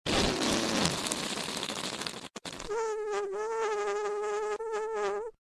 Sans' Whoopie Cushion - Bouton d'effet sonore